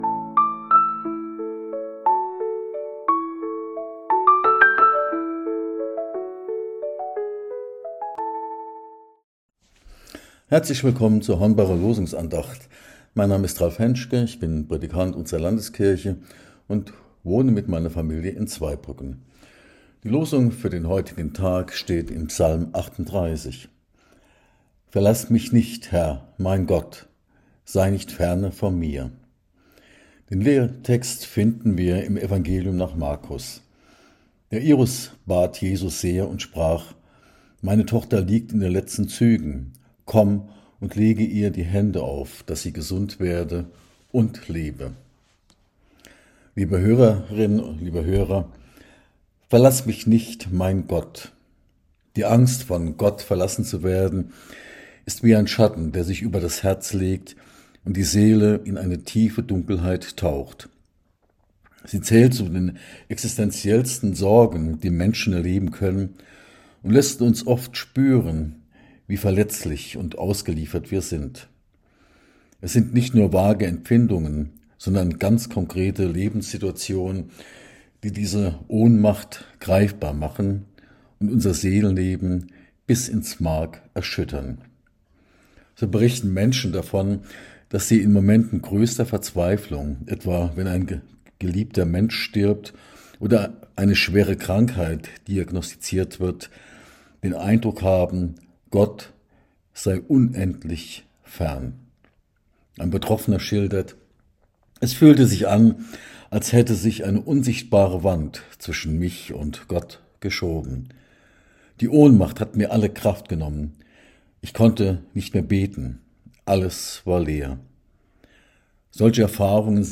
Losungsandacht für Mittwoch, 28.01.2026 – Prot.